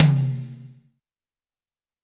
• Big Tom Drum One Shot D Key 25.wav
Royality free tom sample tuned to the D note. Loudest frequency: 707Hz
big-tom-drum-one-shot-d-key-25-qhg.wav